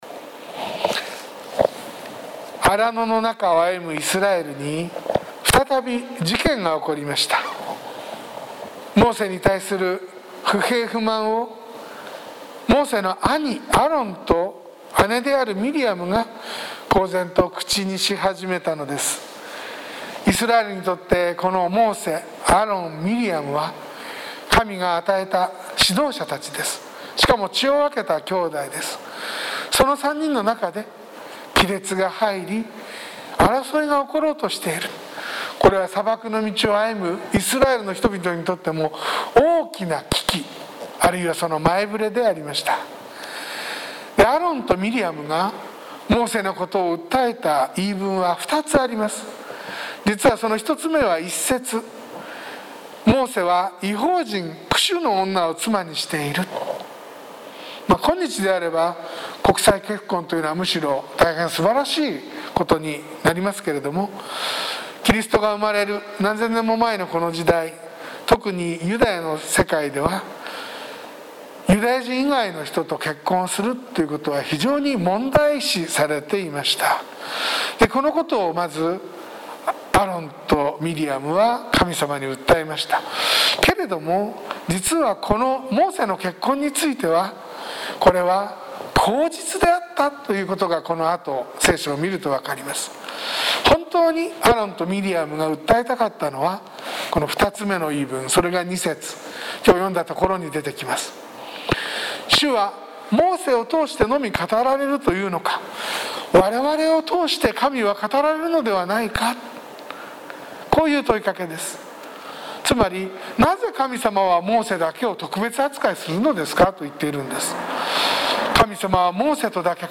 sermon-2020-11-22